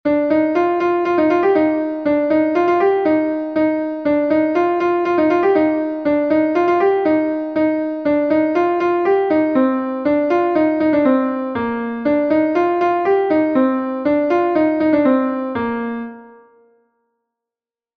Gavotenn Bro-Bourled III est un Gavotte de Bretagne